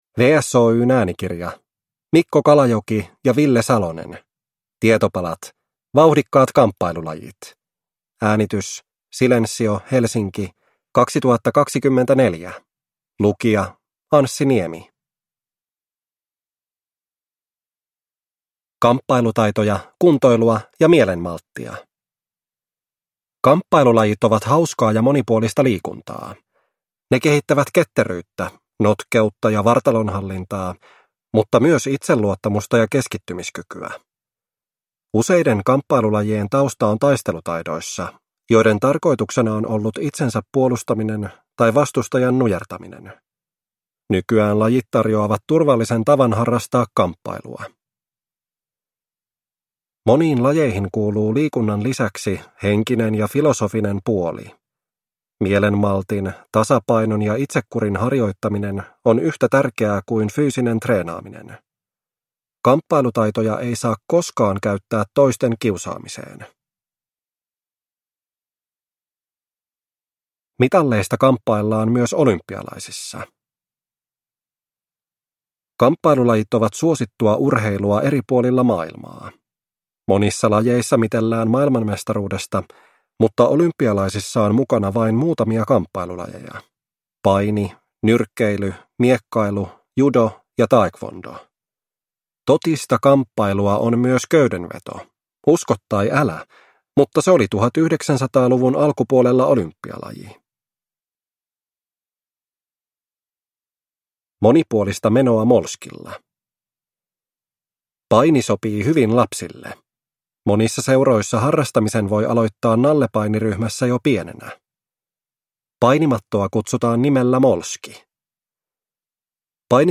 Tietopalat: Vauhdikkaat kamppailulajit – Ljudbok